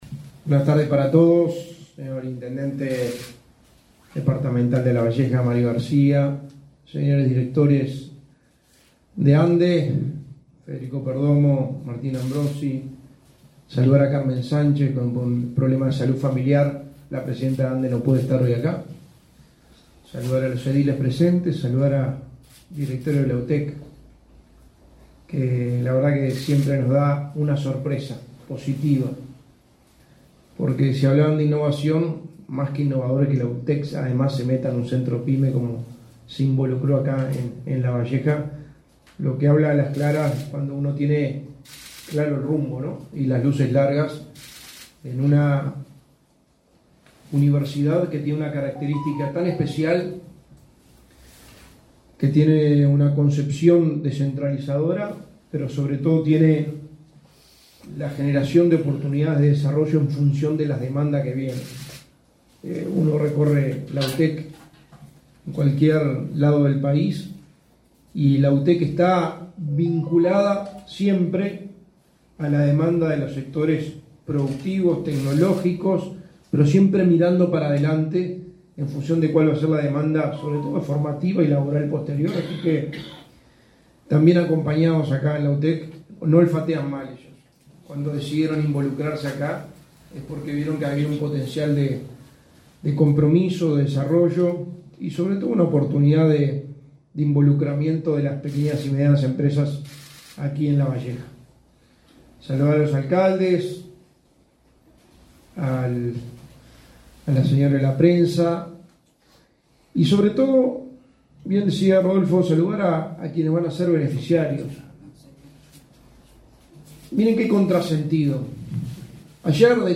Palabras del secretario de la Presidencia, Álvaro Delgado
Con la presencia del secretario de la Presidencia, Álvaro Delgado, fue inaugurado, este 9 de junio, el Centro Pyme de Lavalleja.